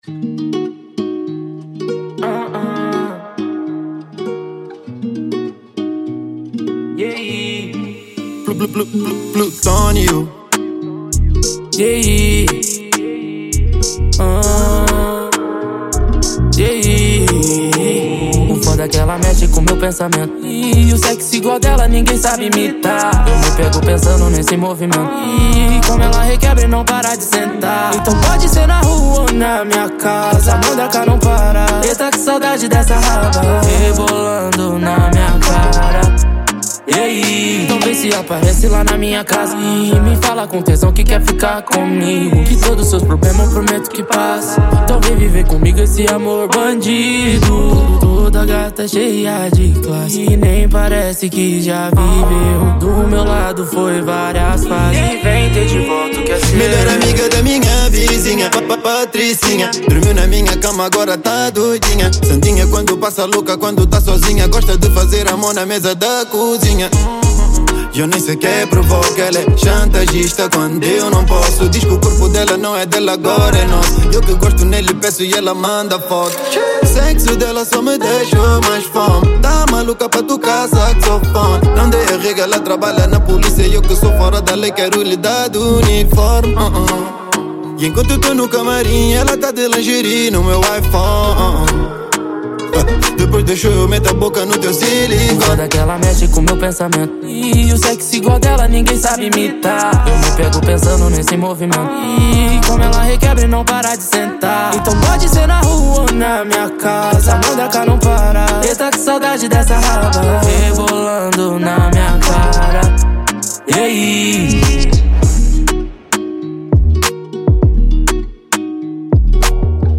Rap
2023 Mp3 Download Hip-Hop Rap